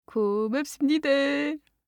알림음(효과음) + 벨소리
알림음 8_고맙습니다3-여자.mp3